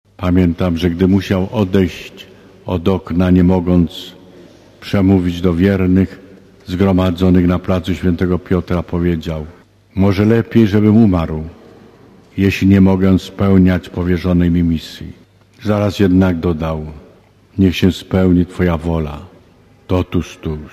* Mówi arcybiskup Stanisław Dziwisz*